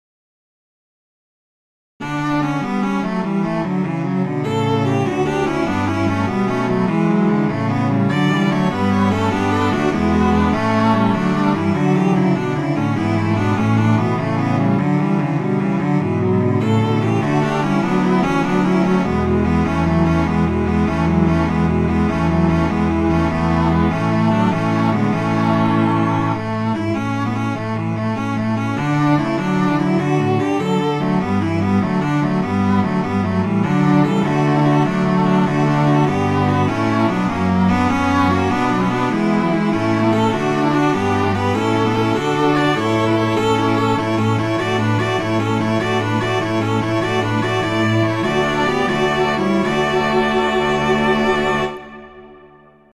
Instrumentation: four cellos
classical, wedding, traditional, easter, festival, love
D major